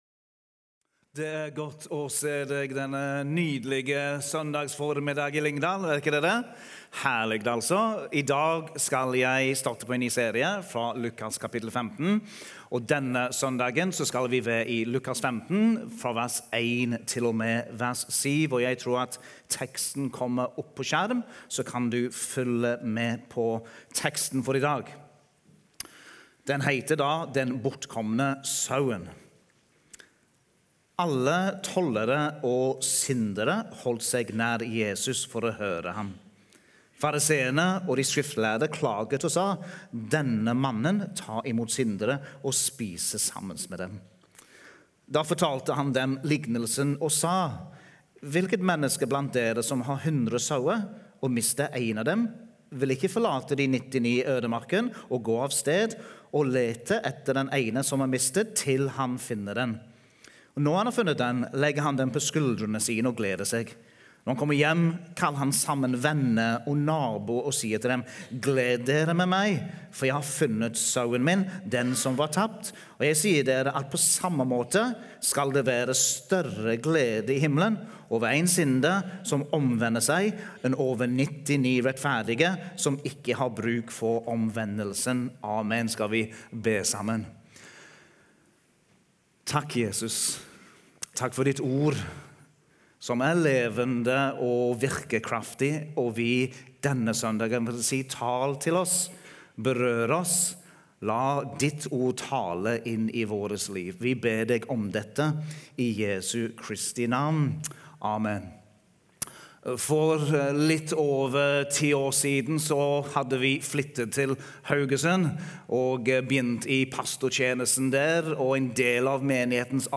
Gudstjeneste